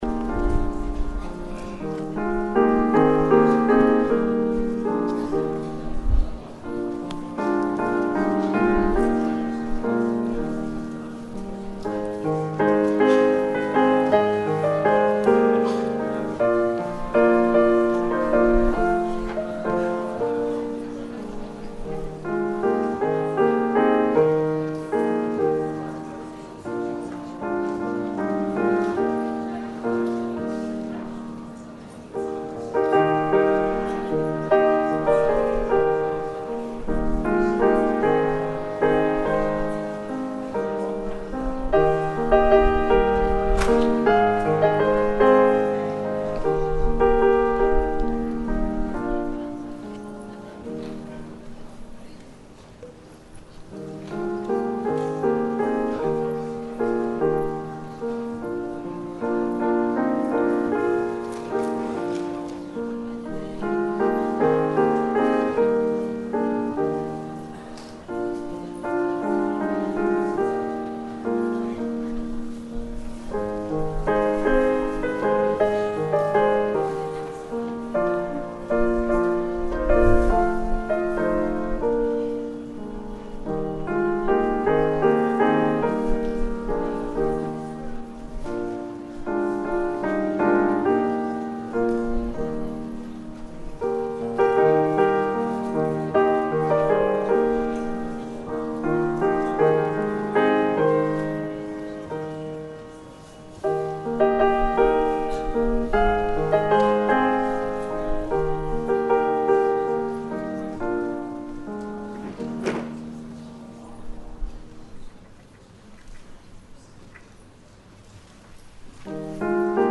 影片版權屬Youtube ) 純鋼琴音樂：
190-Piano.mp3